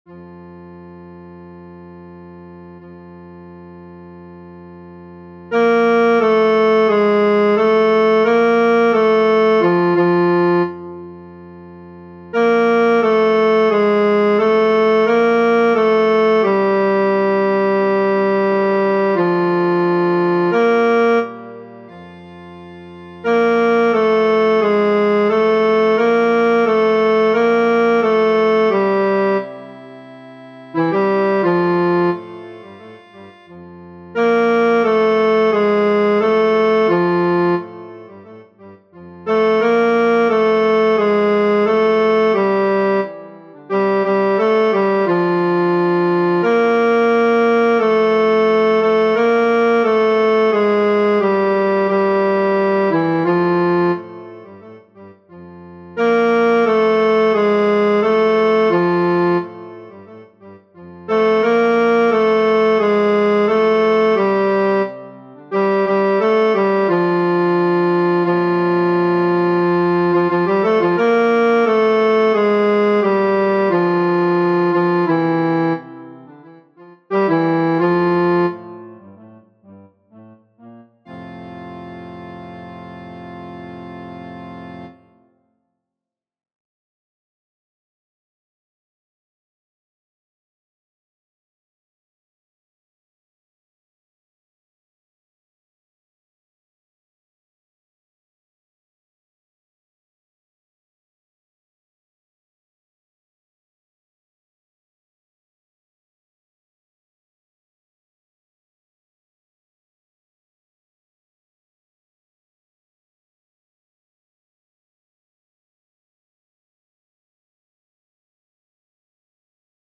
FF:VH_15b Collegium musicum - mužský sbor, FF:HV_15b Collegium musicum - mužský sbor